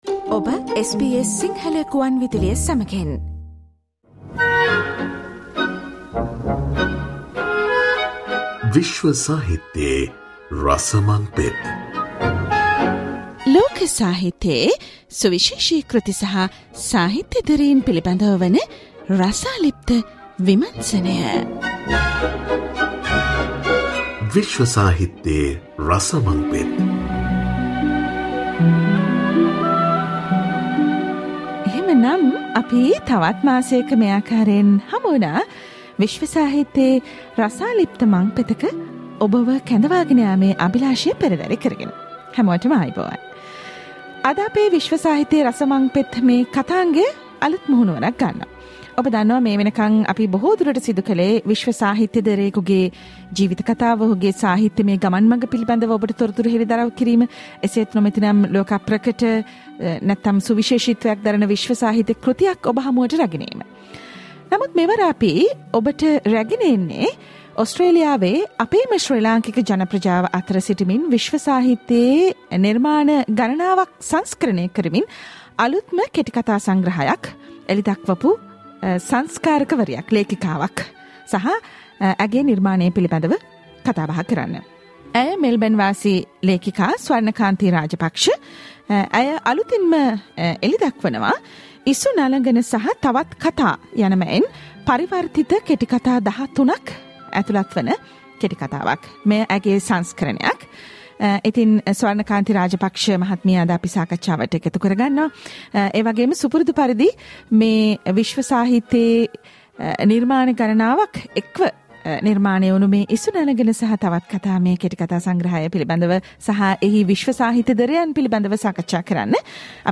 Isu Nalagana_ a Sinhala translated short story collection from Melbourne_ SBS Sinhala World Literary discussion